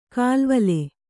♪ kālvale